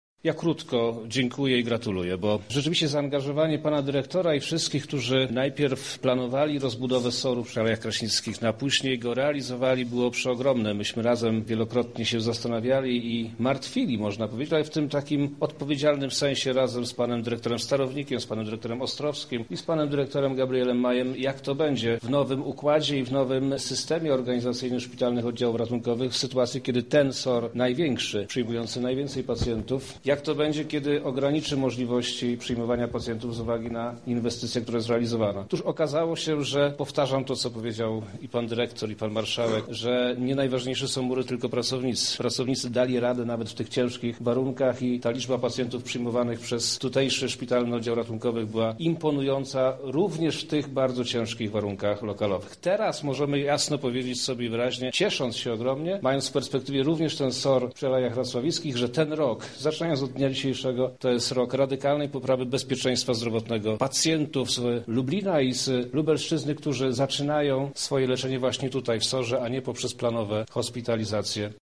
Podczas prezentacji nowo oddanego oddziału nie zabrakło także obecności lubelskich polityków.
Słowa wicemarszałka, co do ważności placówki potwierdził także wojewoda lubelski Przemysław Czarnek